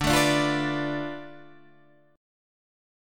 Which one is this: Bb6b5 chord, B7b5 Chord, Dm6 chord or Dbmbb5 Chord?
Dm6 chord